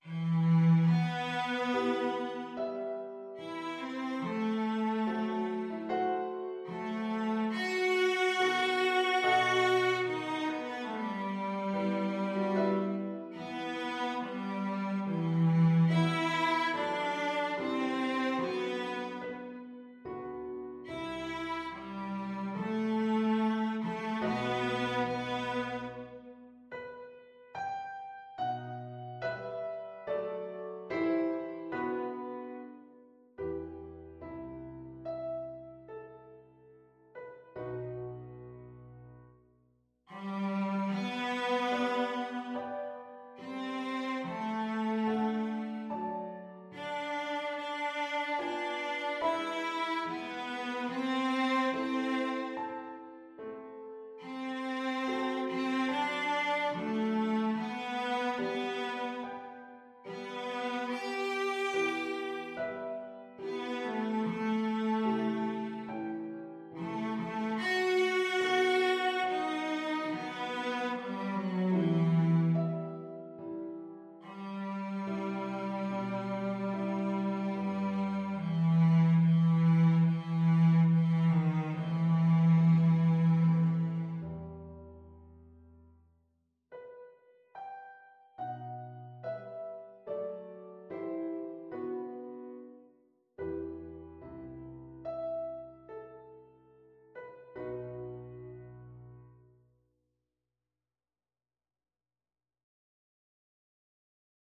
Cello
4/4 (View more 4/4 Music)
E minor (Sounding Pitch) (View more E minor Music for Cello )
Largo =c.72
Classical (View more Classical Cello Music)